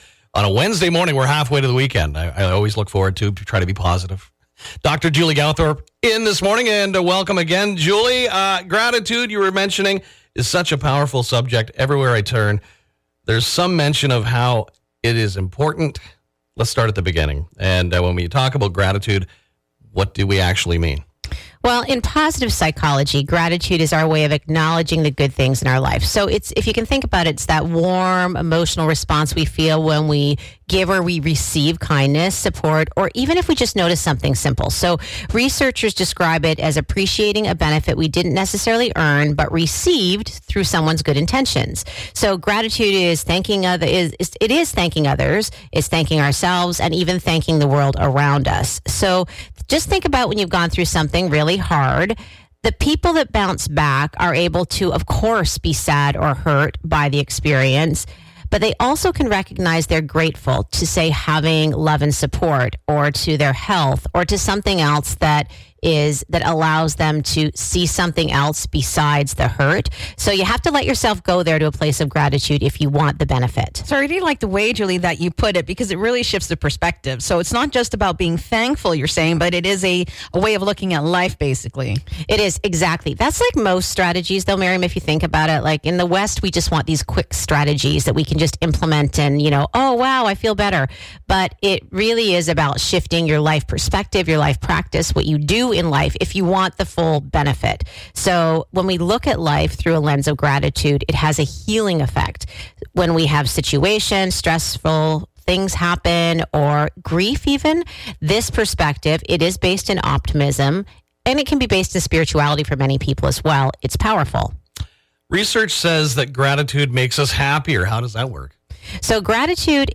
sit down to talk about how to tap into this